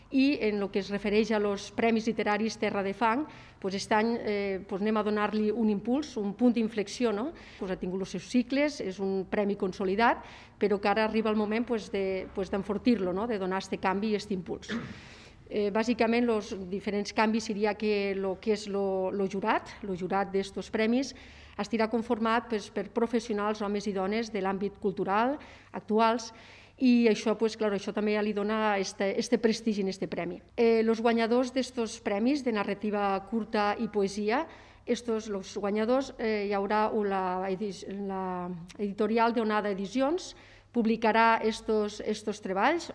Anna Giménez és regidora de Foment Cultural: